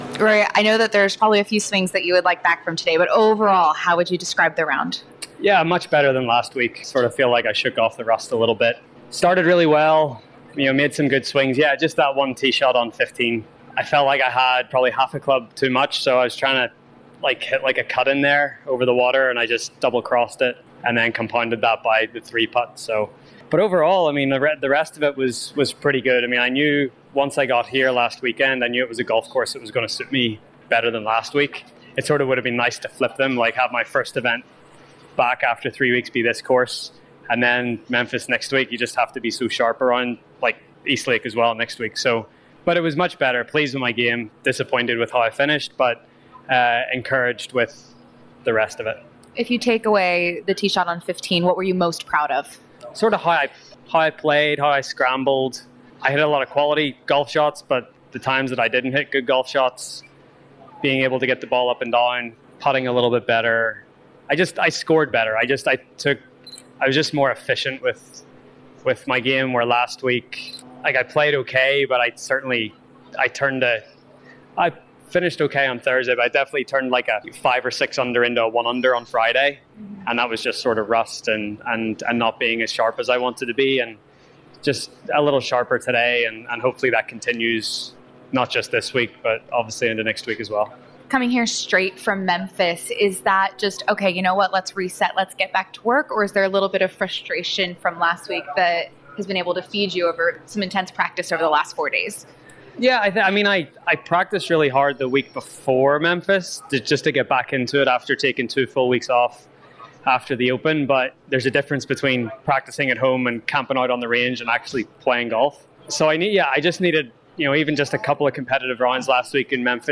Rory McIlroy talks to the media after near perfect Round 1 at the BMW Championship.